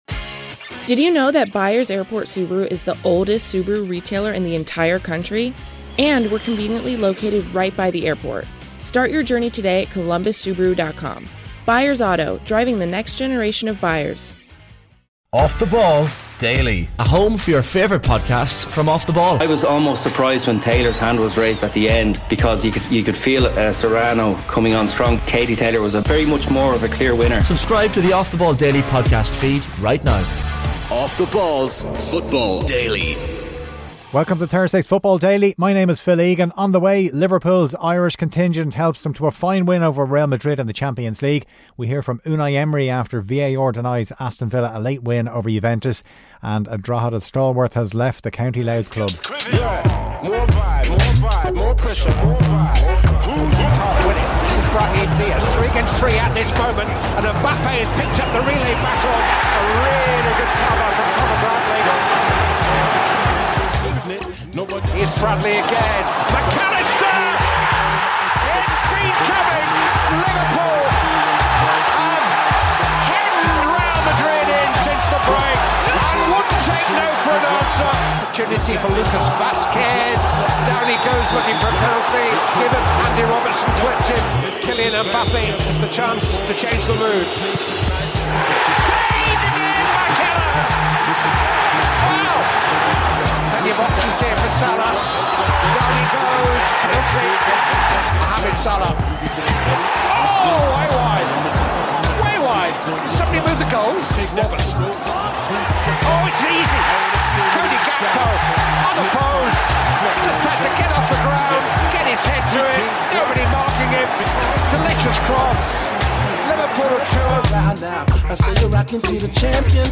Kelly Cates unpicks the reaction to the new head coach at Tottenham Hotspur alongside former Spurs keeper Paul Robinson.
Plus, hear from Scotland's Andy Robertson, who were beaten by Ivory Coast at the Hill Dickinson Stadium.